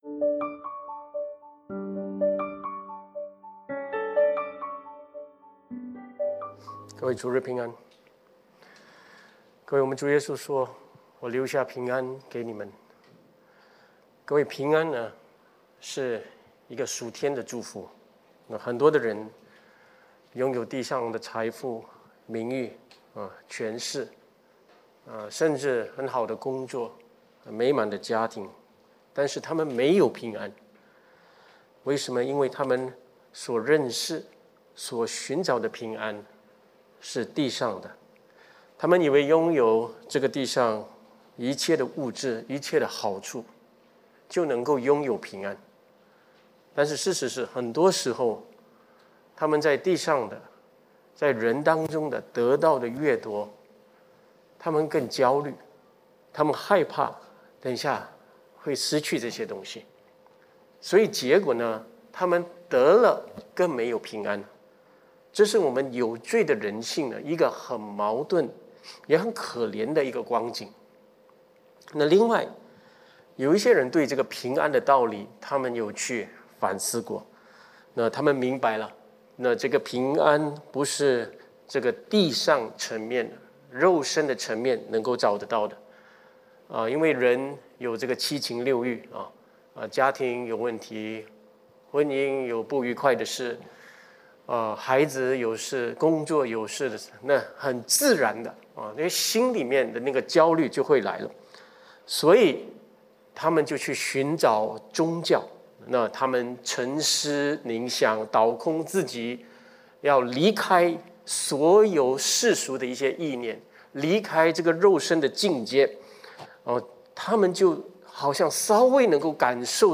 22-31》将一切的事指教我们的圣灵[12月20日约翰福音释经讲道]
主日信息